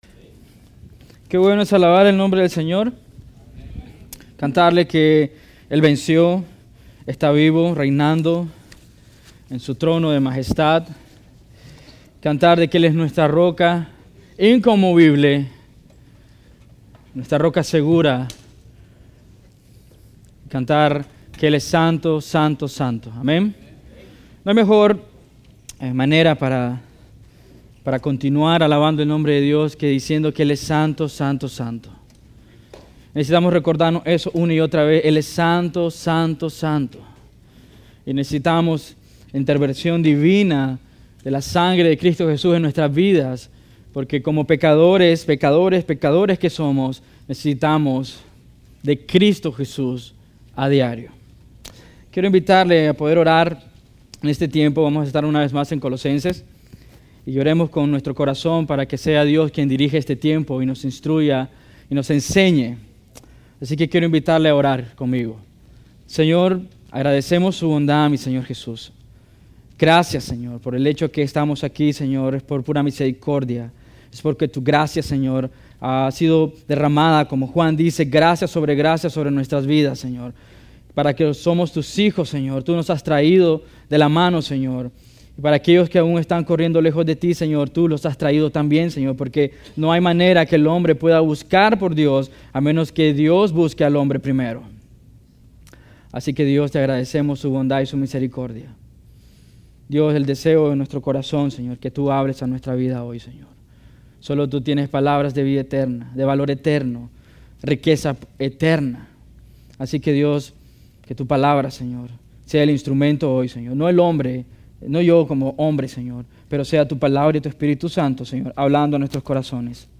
Serie de sermones: Vida nueva en Cristo Categoria: Vida Cristiana Idioma: es Anterior | Siguiente